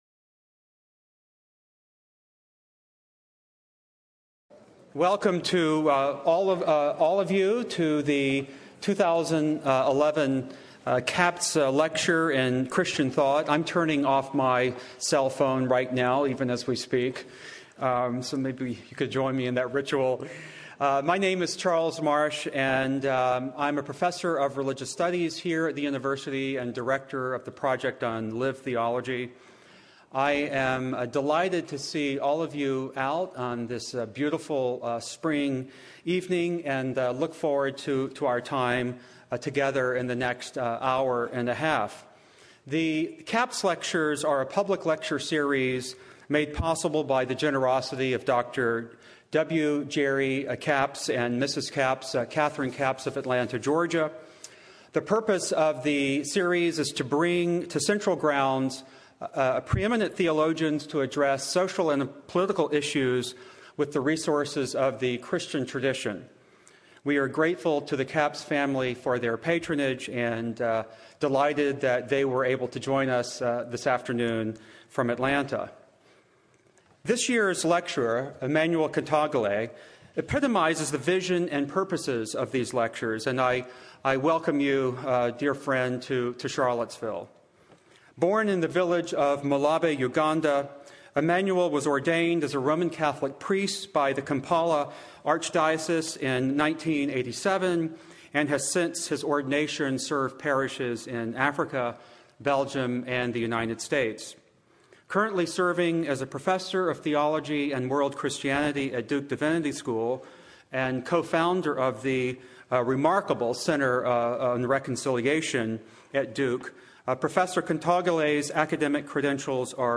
Audio Information Date Recorded: March 28, 2011 Location Recorded: Charlottesville, VA Audio File: Download File » This audio is published by the Project on Lived Theology (PLT).
CappsLecture.mp3